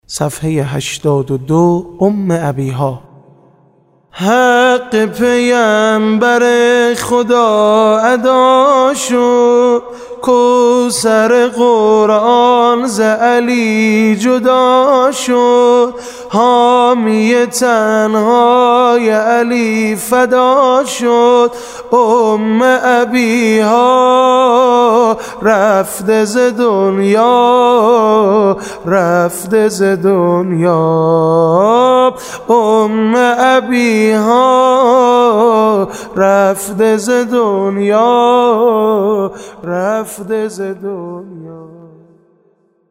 نوحه شهادت حضرت زهرا(س) با سبك سنتي - (حـق پیغمبـر خـدا ادا شد)